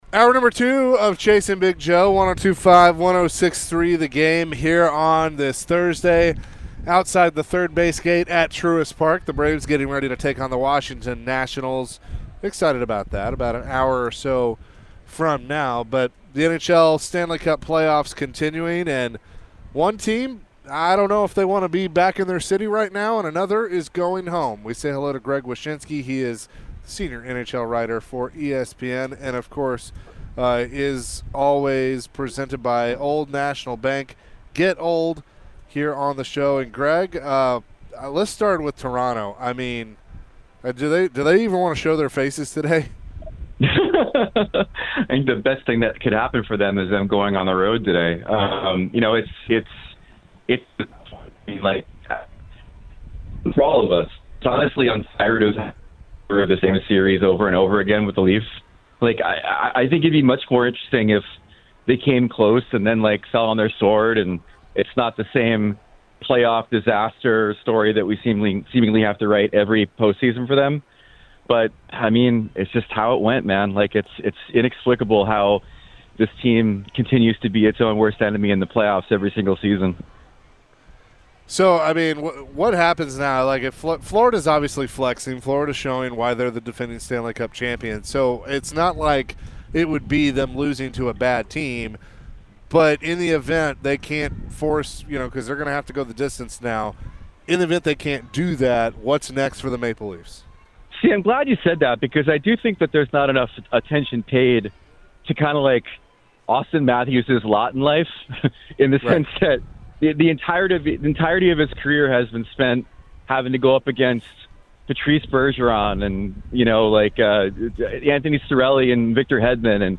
Later in the hour, the guys answered some phones and played Celebrity Birthdays.